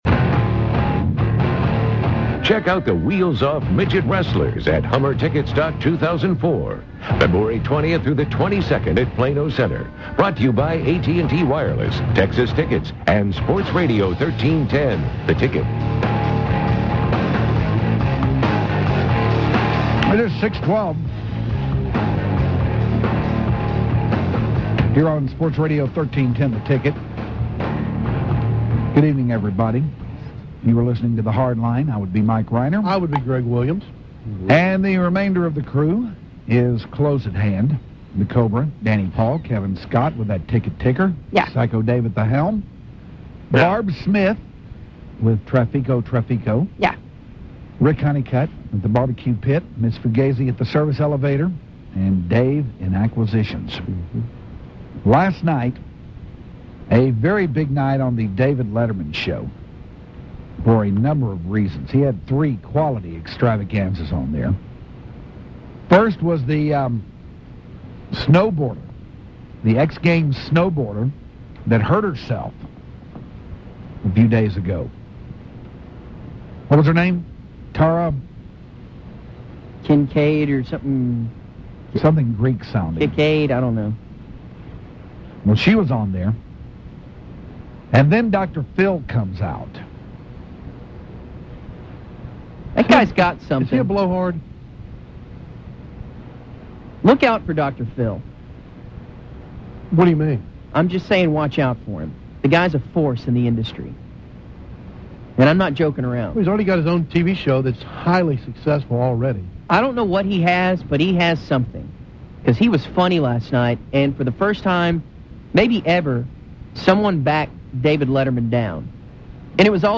The Hardliners discuss and listen to audio of The Beatles first appearance on The Ed Sullivan Show